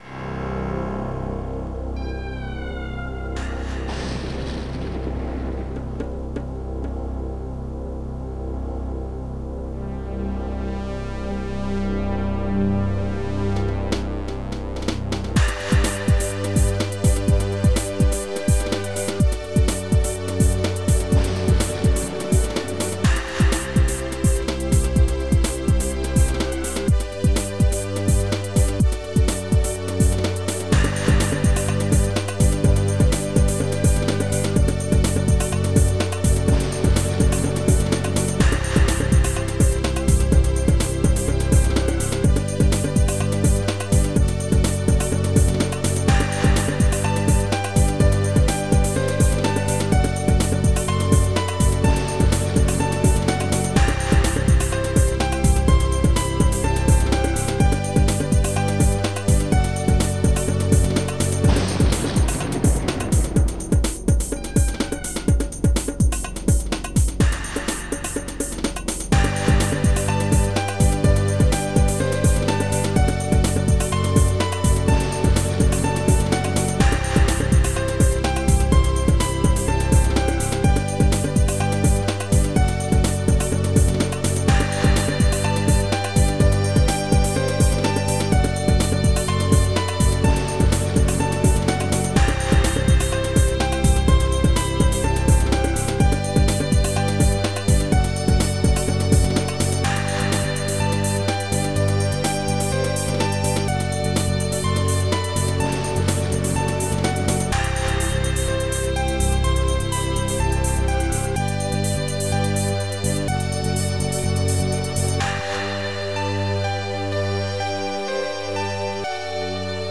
(S) Stereo
Dreamhouse
14 channels
125 BPM